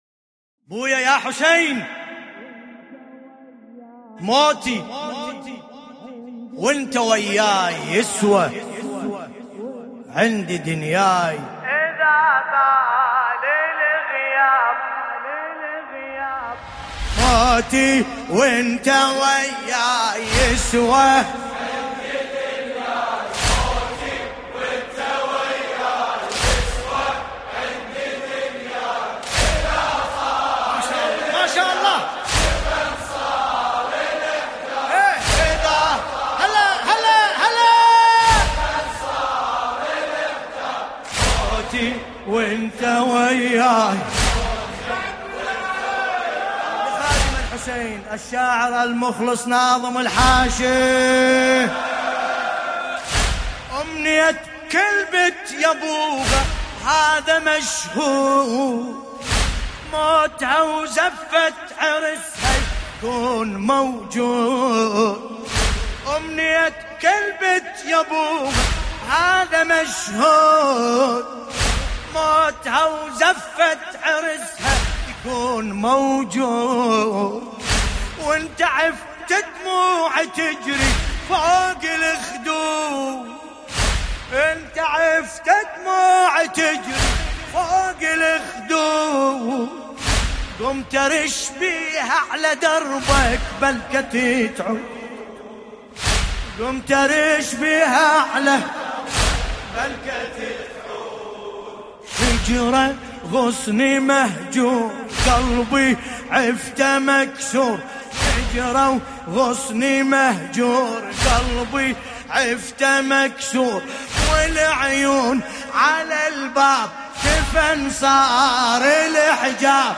ملف صوتی موتي بصوت باسم الكربلائي
قصيدة
المناسبة : عن العليلة الزمن : ليلة 3 محرم 1440 هـ المكان : هيئة شباب علي الأكبر - لندن